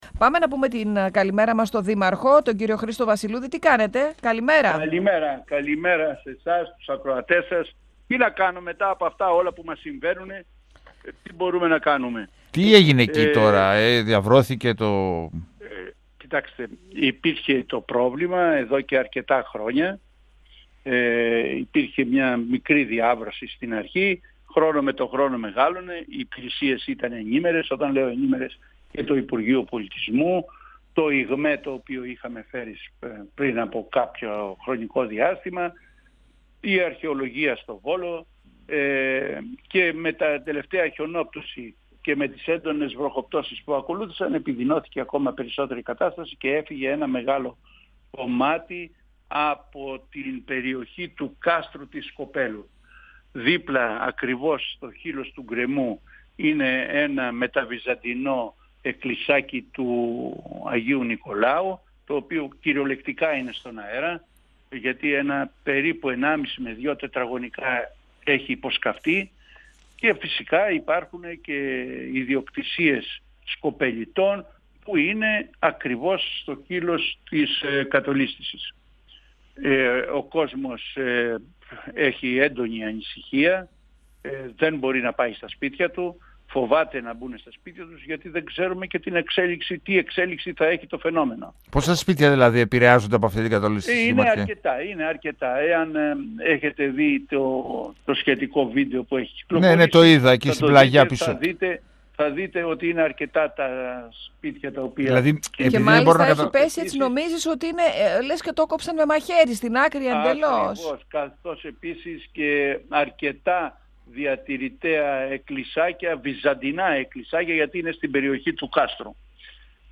O δήμαρχος Σκοπέλου Χρήστος Βασιλούδης,  στον 102FM του Ρ.Σ.Μ. της ΕΡΤ3